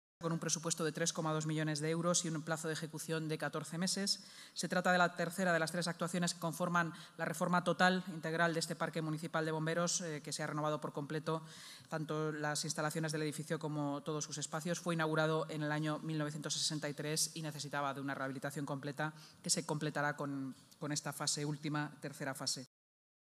Nueva ventana:Declaraciones de la portavoz municipal, y delegada de Seguridad y Emergencias, Inmaculada Sanz